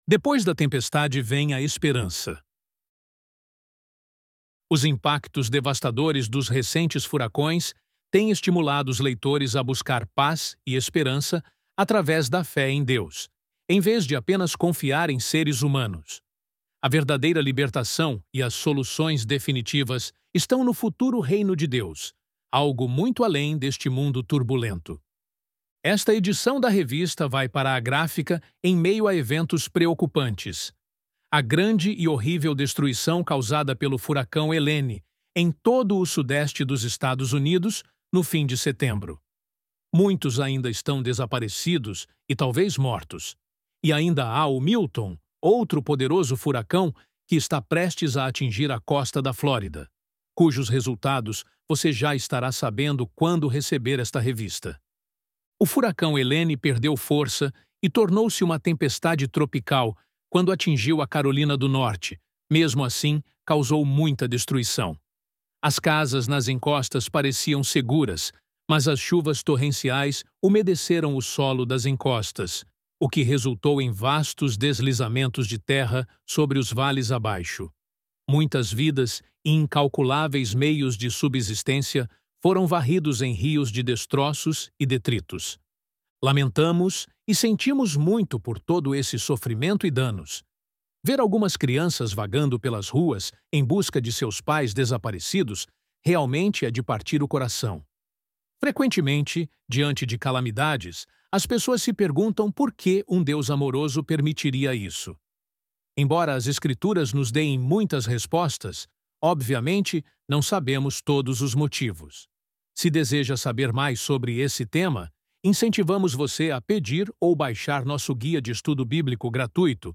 ElevenLabs_Depois_da_Tempestade_Vem_a_Esperança.mp3